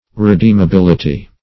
\Re*deem`a*bil"i*ty\